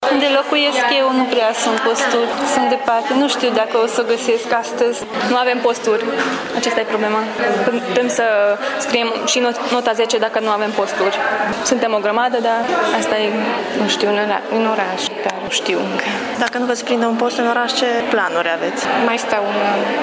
Viitorii profesori sunt dezamăgiți pentru că, spun ei, nu sunt suficiente posturi.